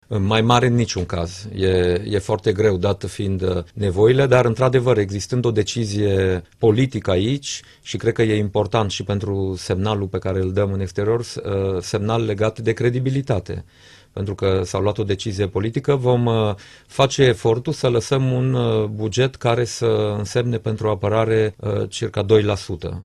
La Interviurile Europa FM, premierul Dacian Cioloș a explicat că anul viitor Armata va primi 2 la sută din PIB, dar nu mai mult.